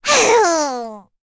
Cecilia-Vox_Sad_c.wav